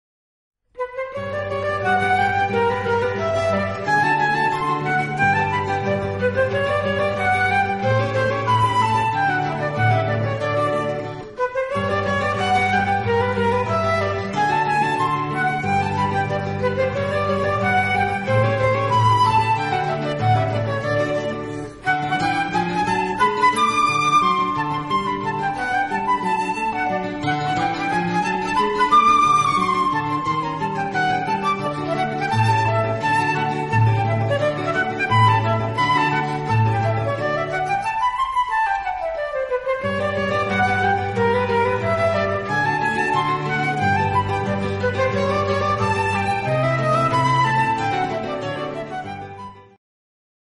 guitar Two seminal pieces for guitar and flute duet.